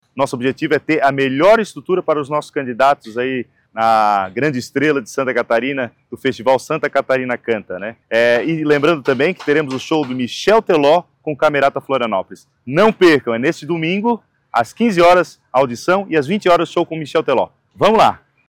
O presidente da Fundação Catarinense de Cultura e coordenador do Festival, Guilherme Botelho, falou da expectativa para o grande dia: